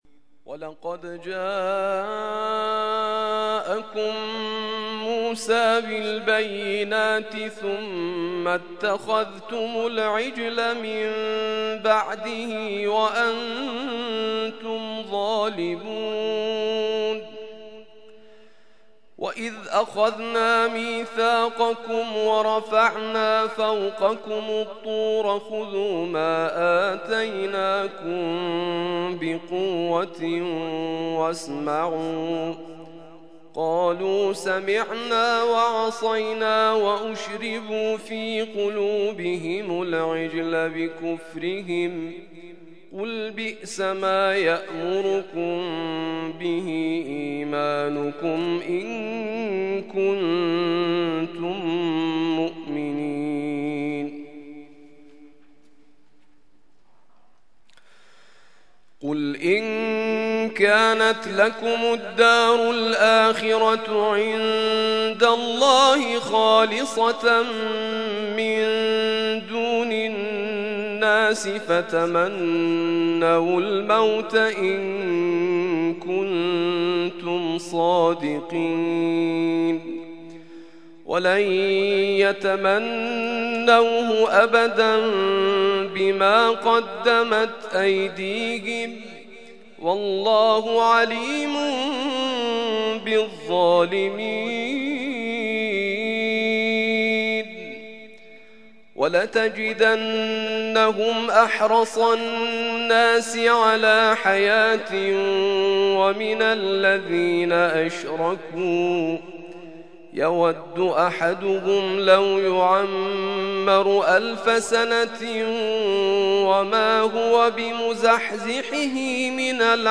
ترتیل خوانی جزء ۱ قرآن کریم در سال ۱۳۹۱